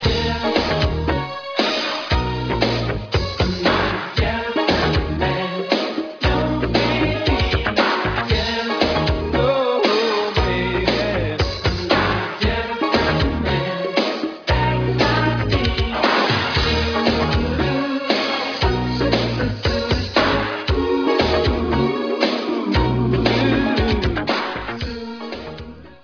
background vocals and keyboards